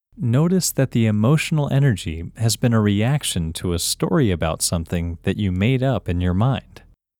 OUT – English Male 15